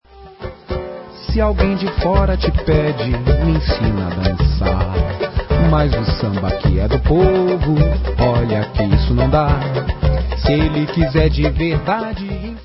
Sample                       Sample (Refrain)